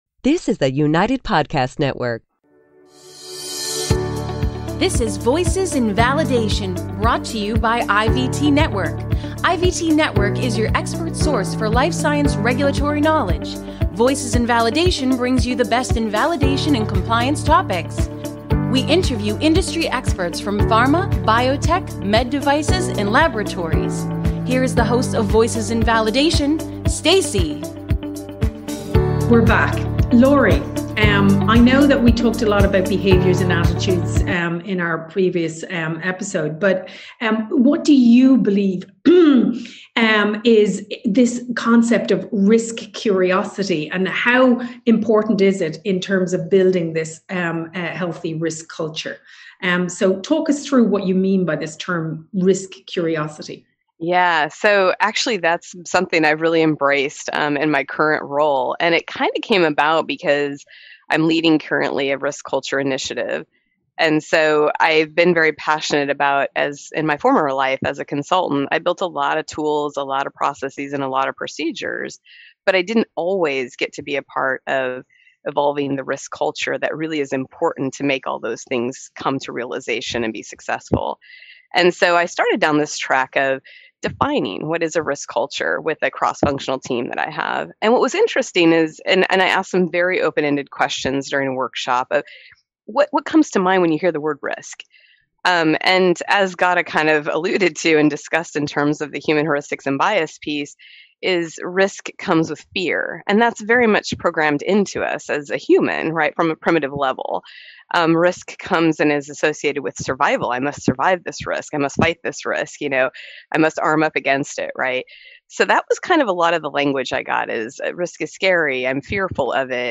for a robust conversation around the risk curious concept and expand into the why’s and why not and the views of this practice under PICs. The group also reviews the need for training and development around risk, formalization of a risk management process, a top-down approach to building culture, and the need for critical thinking and risk-based decision-making.